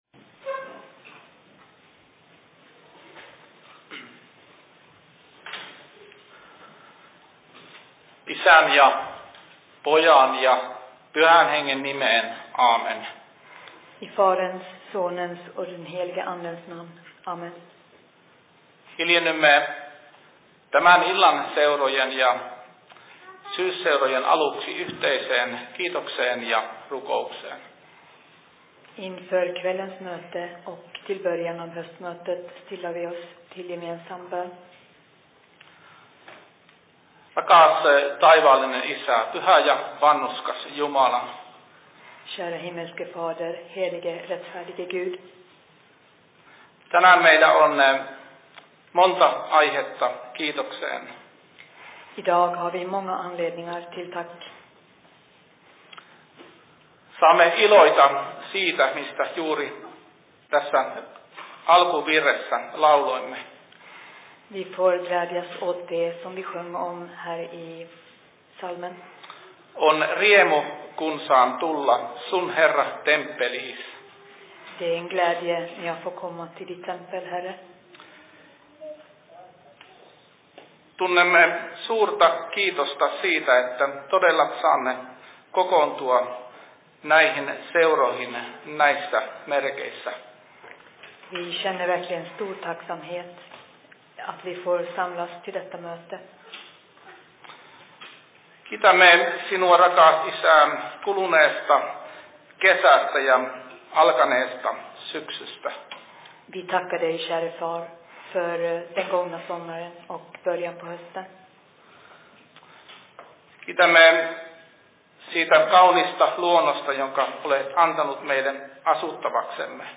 Höstmöte/Fi Se Predikan I Dalarnas Fridsförening 25.09.2020 19.03
Paikka: SFC Dalarna
2020 Simultaanitulkattu Suomi, Ruotsi Kirja